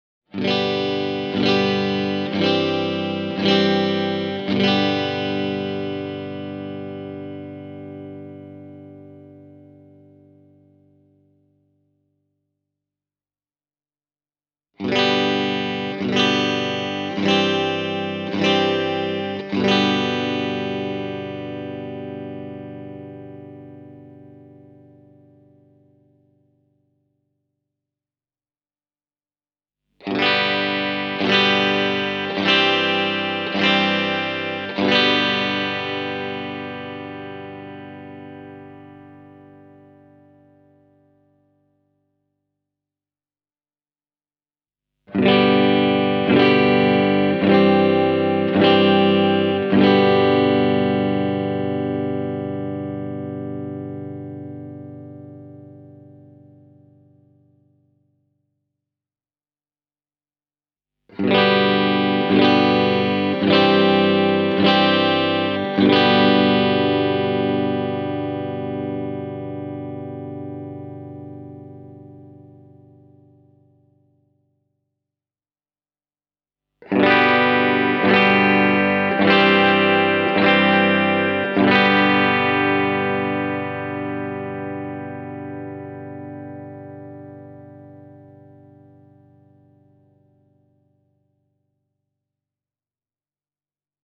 Ensimmäinen soundiesimerkki esittelee PRS SE Custom 24 Roasted Maplen puhtaita perussoundeja. Klippi alkaa puolitetuilla mikrofoneilla: